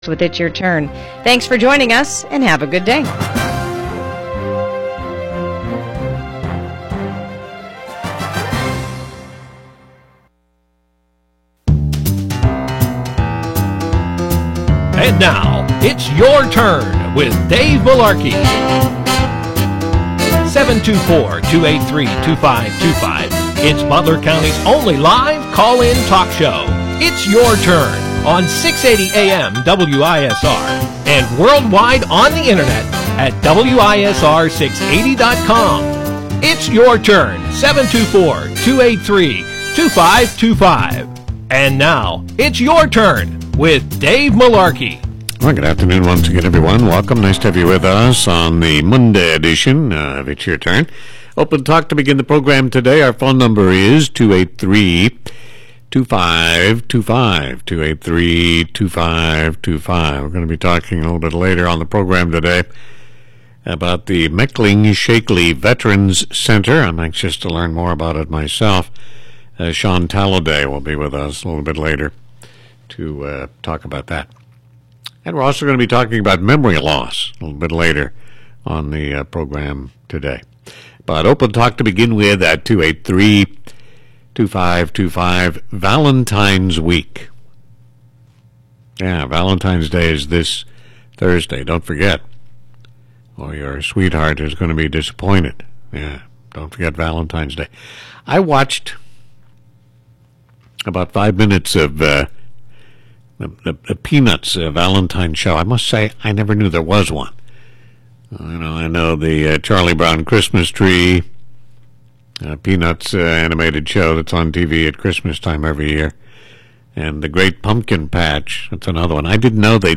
OPEN TALK